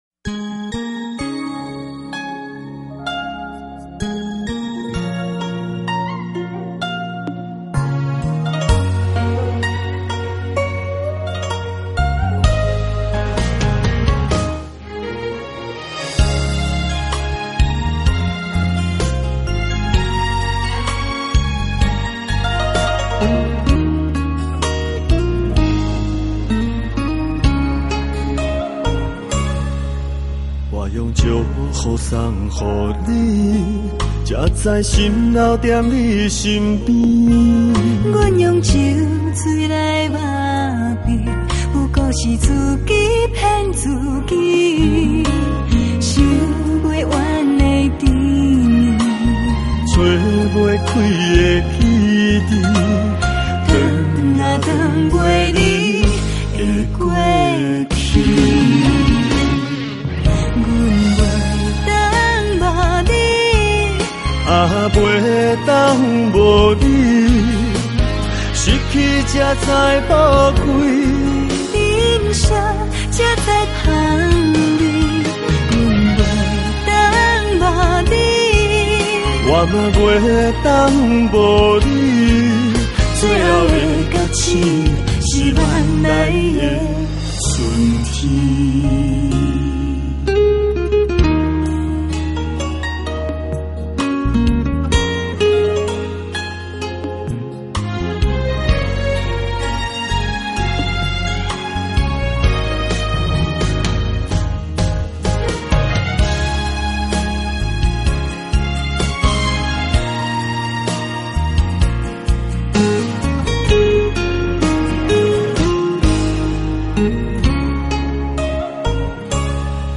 【音樂類型】：台語歌曲